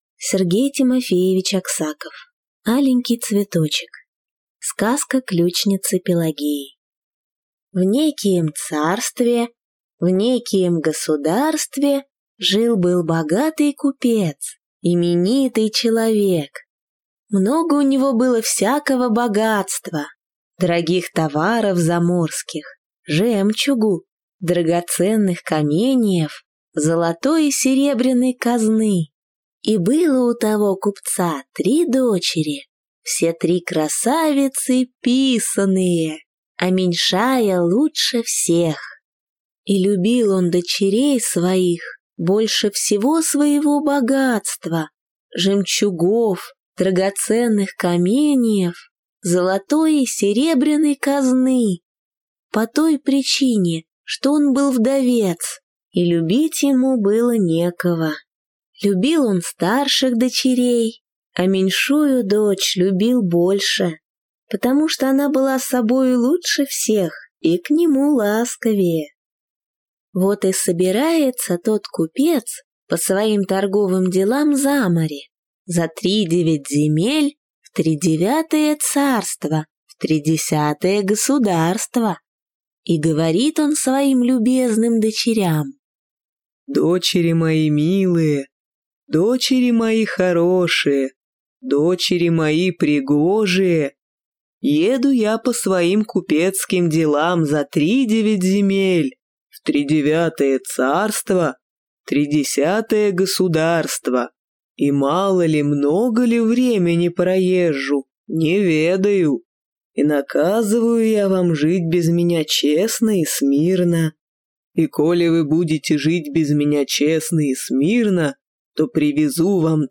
Аудиокнига Аленький цветочек | Библиотека аудиокниг